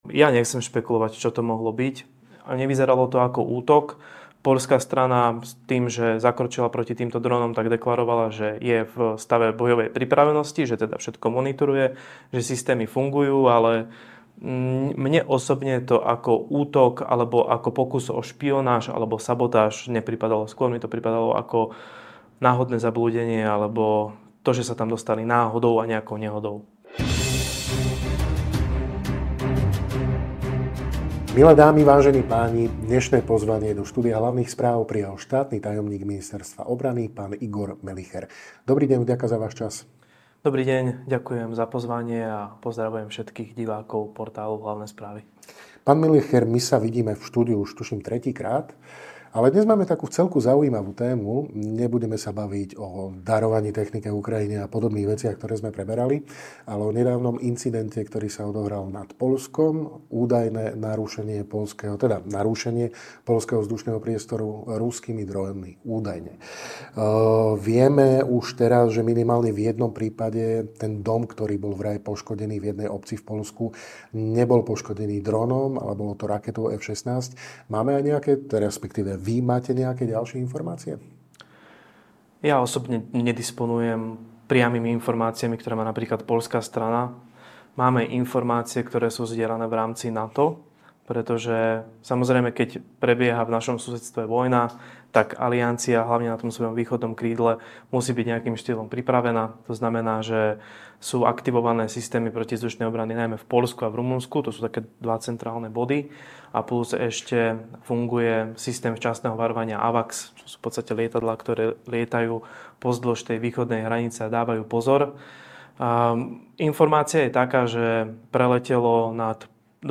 Rozprávali sme sa so štátnym tajomníkom Ministerstva obrany SR, Mgr. Igorom Melicherom.